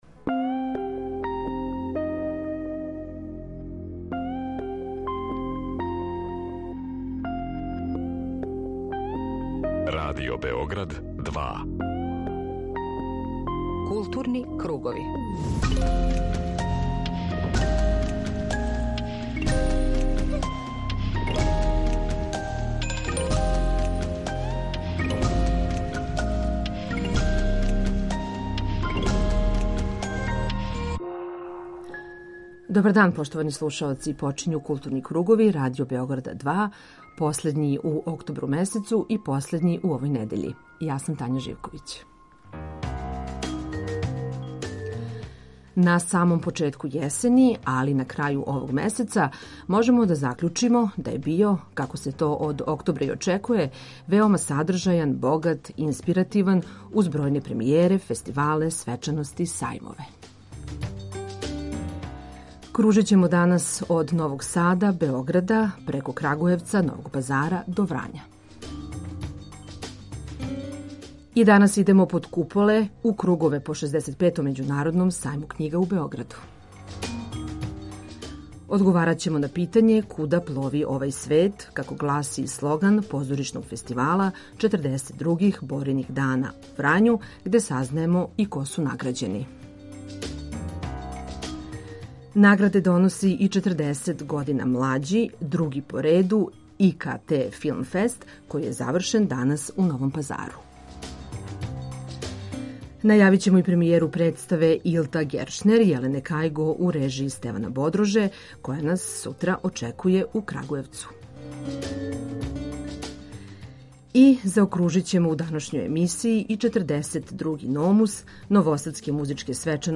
Група аутора Централна културно-уметничка емисија Радио Београда 2.
И данас пратимо све актуелности са 65. Међународног сајма књига у Београду, који је ушао у своју завршницу, из нашег импровизованог студија у Хали 4 Београдског сајма.